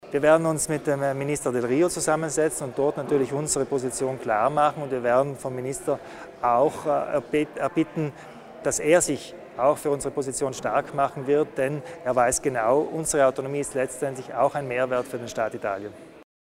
Landeshauptmann Kompatscher erläutert die nächsten Schritte zum Ausbau der Autonomie